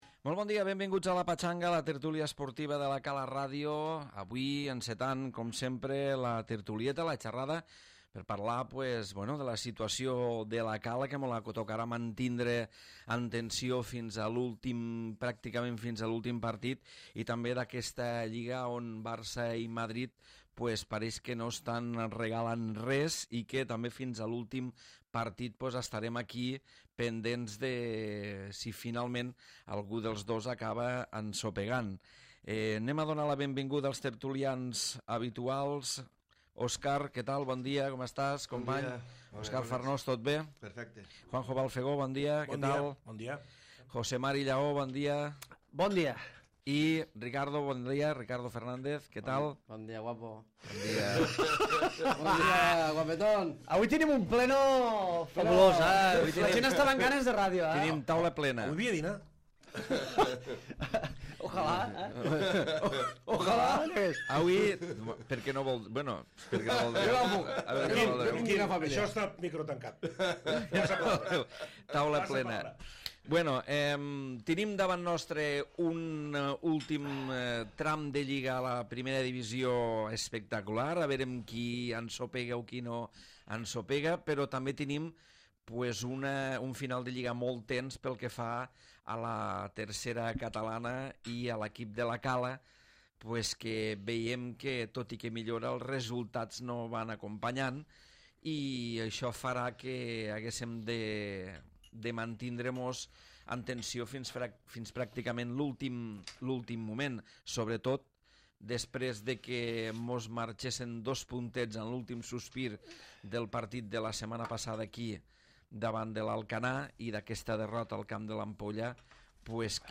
Tertúlia esportiva i futbolística amb l'actualitat del Barça i el Madrid